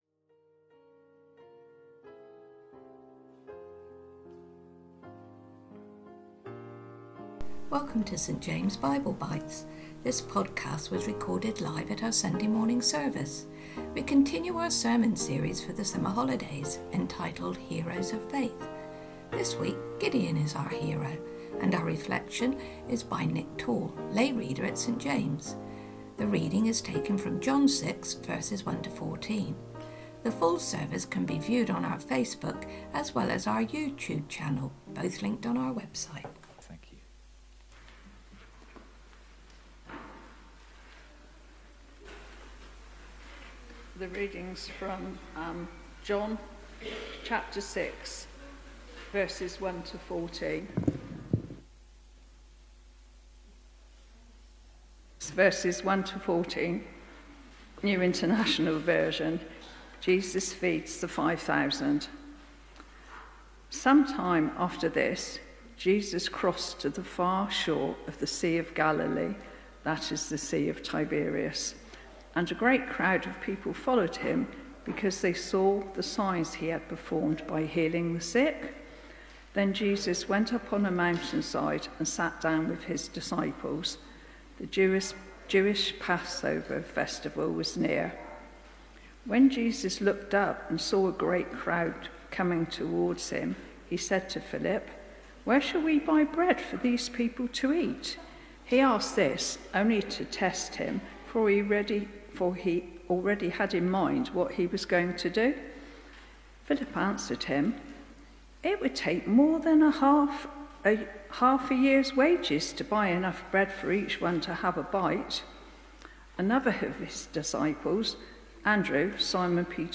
Sunday Talks